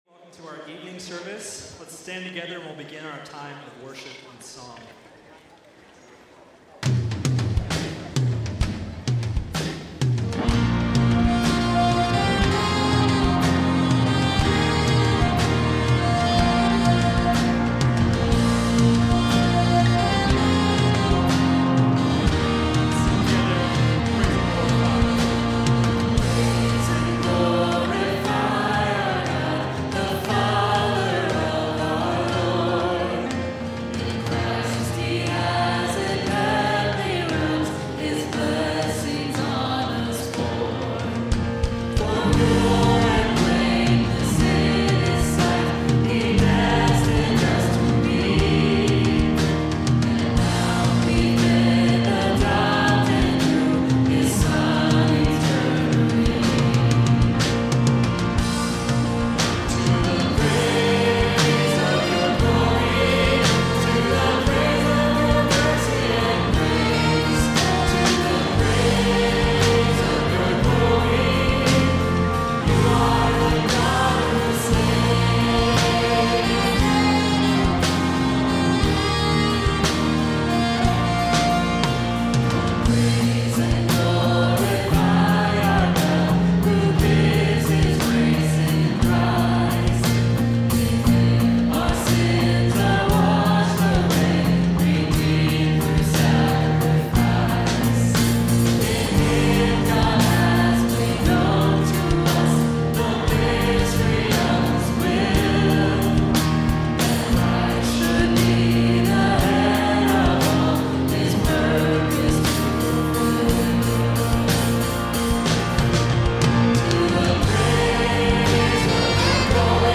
Evening Baptism Service